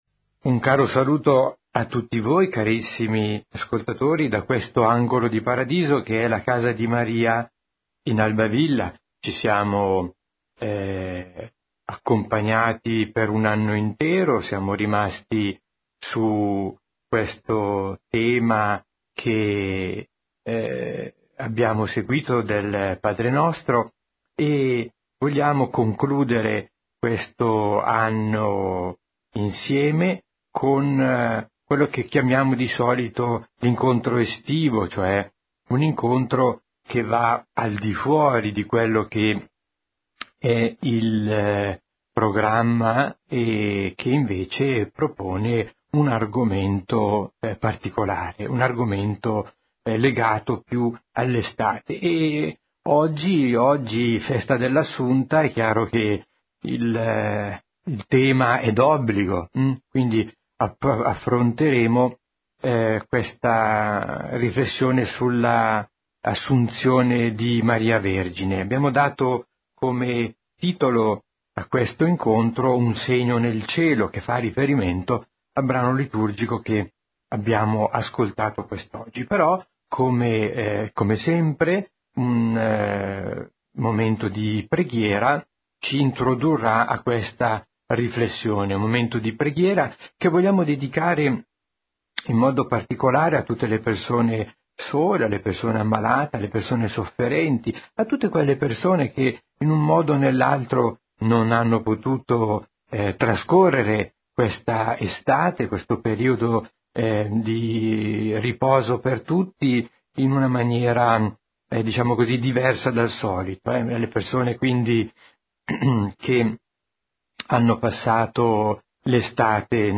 Un Segno dal Cielo meditazione